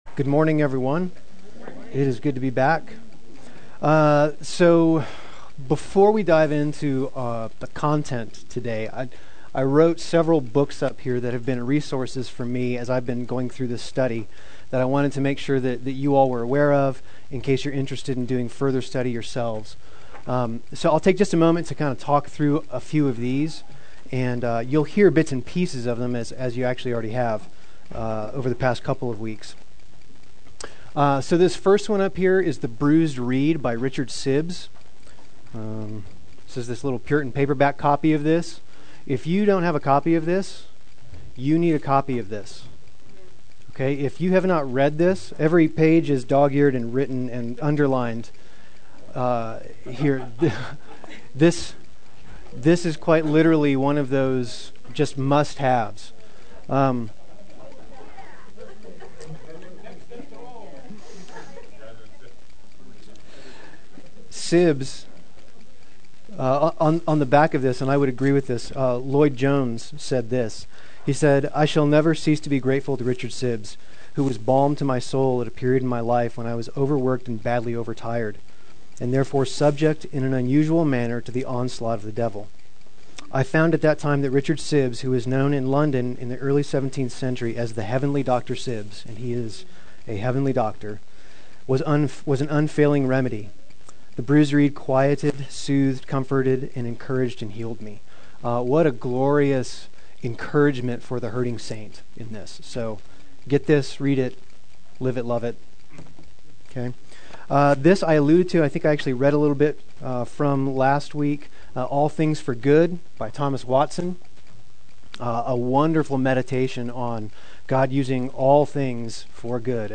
Trials Endured Adult Sunday School